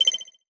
Message Bulletin Echo 5.wav